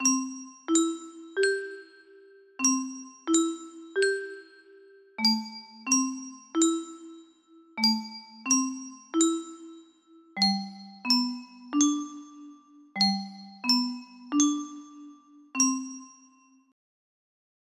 music box- Me music box melody